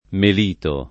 vai all'elenco alfabetico delle voci ingrandisci il carattere 100% rimpicciolisci il carattere stampa invia tramite posta elettronica codividi su Facebook Melito [ mel & to ] top. (Camp.) — due comuni: M. di Napoli , M. Irpino — anche cognome